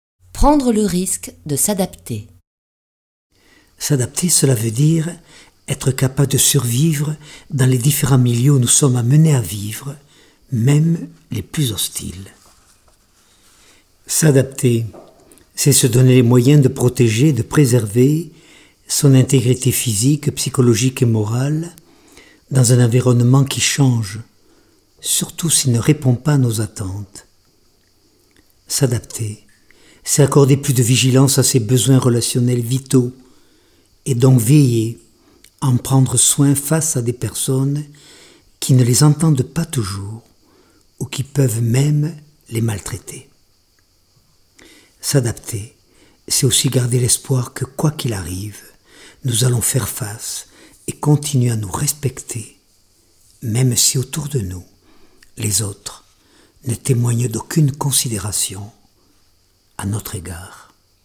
Diffusion distribution ebook et livre audio - Catalogue livres numériques
Lu par Jacques Salomé Durée : 78 minutes 14 , 40 € Ce livre est accessible aux handicaps Voir les informations d'accessibilité